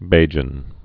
(bājən)